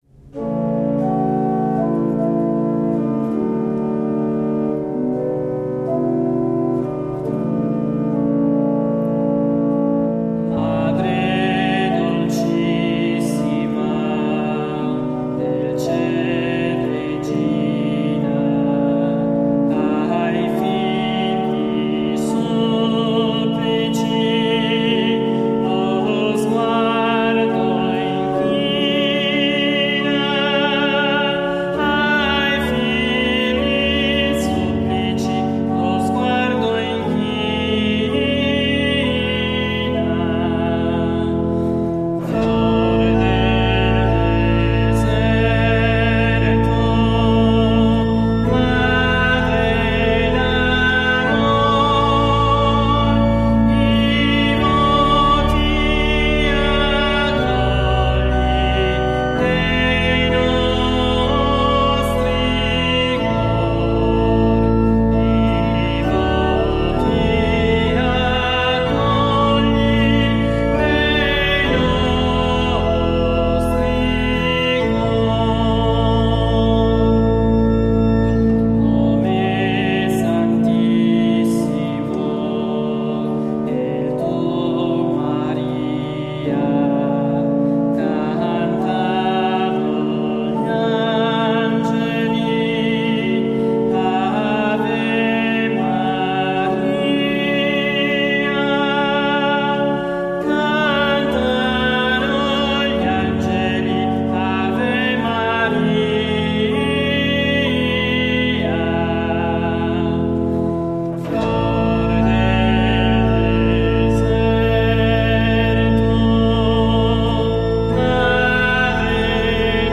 All'organo Agati
organista e solista